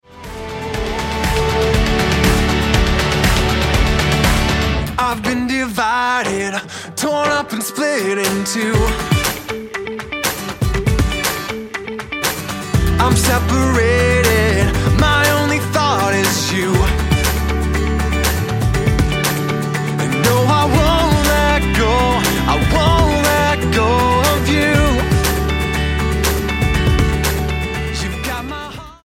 Minneapolis-based rockers
Style: Rock